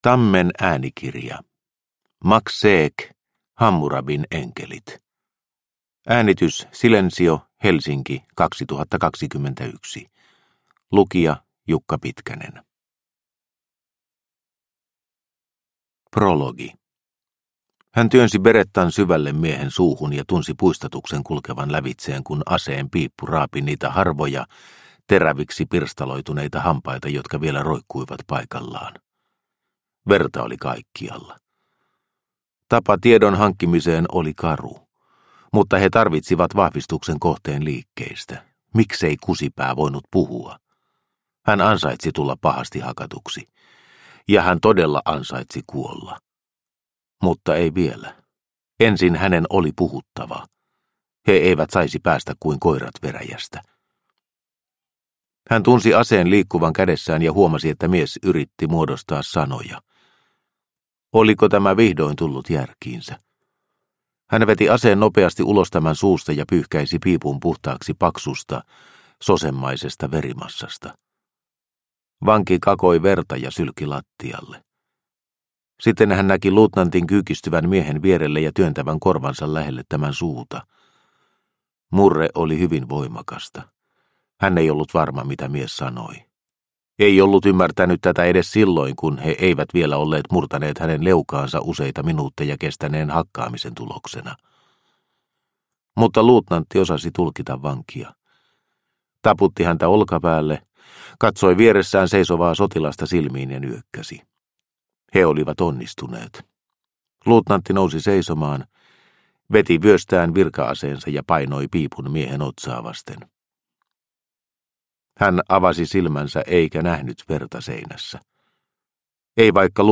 Hammurabin enkelit – Ljudbok – Laddas ner